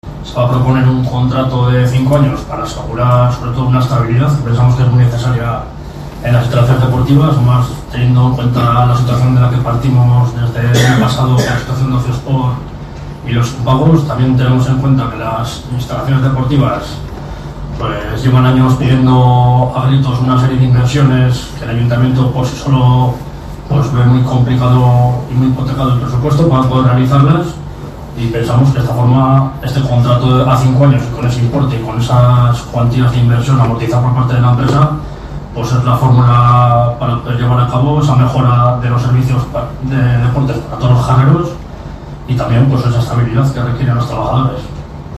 Por su parte, el equipo de gobierno, a través del concejal Borja Merino razonó la propuesta así.
BORJA-PLENO_.mp3